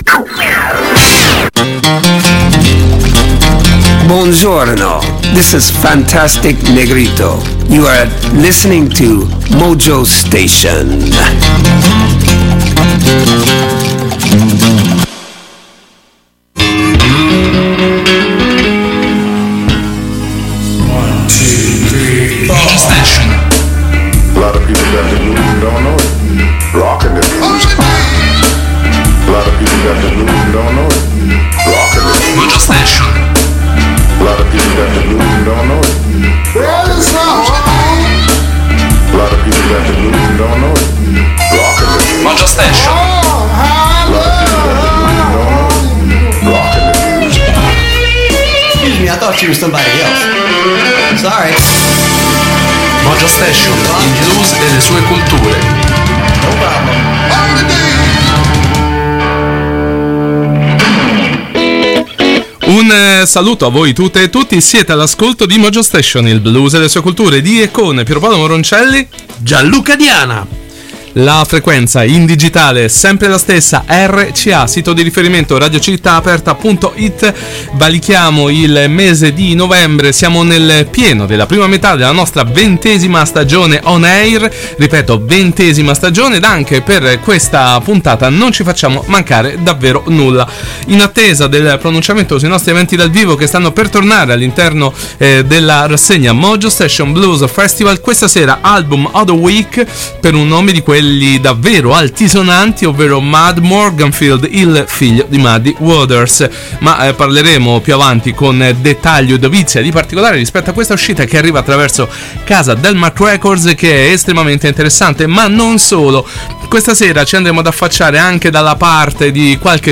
Lo spirito della musica blues dagli albori fino ai paesaggi musicali contemporanei